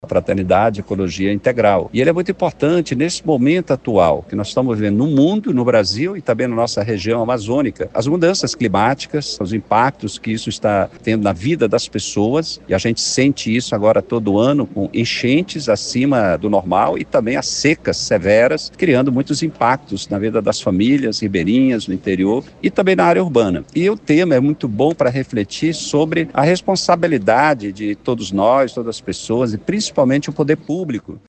O evento foi realizado no plenário da Câmara Municipal de Manaus nesta quinta-feira, 27 de março.